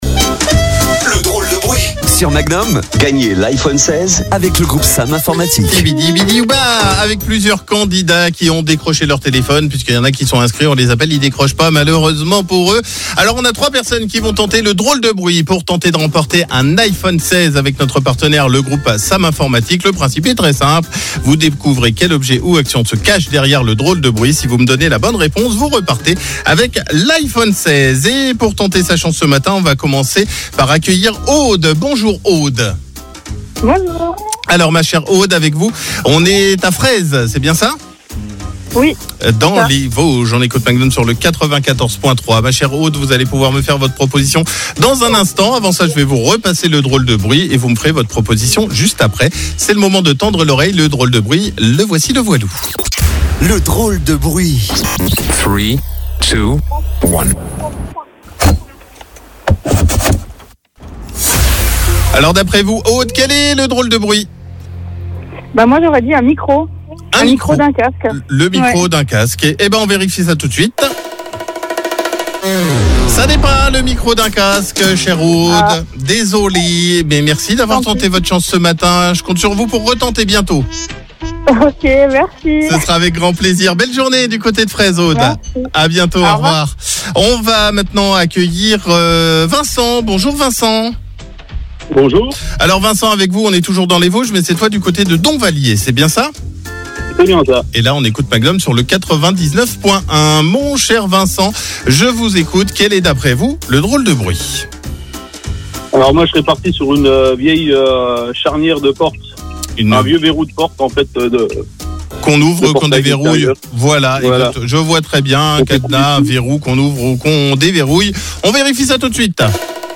Découvrez quel objet ou quelle action se cache derrière le DROLE DE BRUIT en écoutant CLUB MAGNUM entre 9h et 13h.